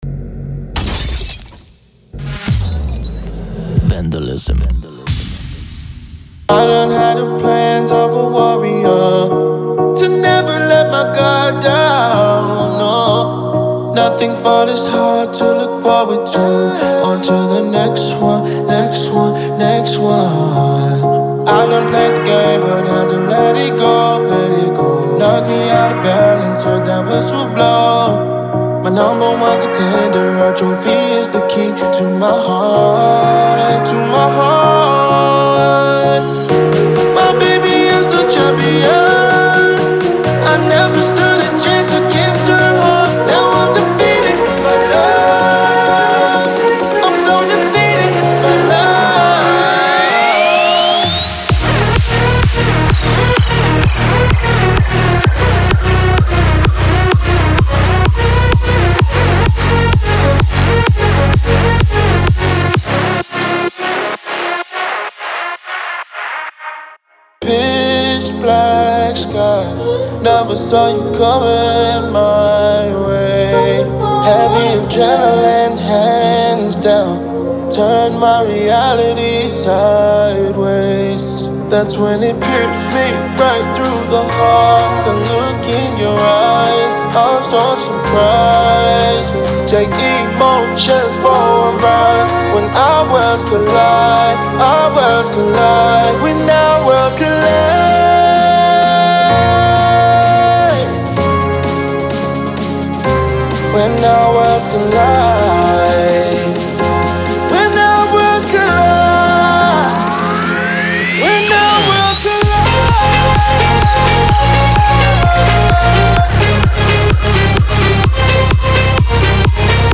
• Ultra Pop Vocals
• Ultra Tropical House Vocals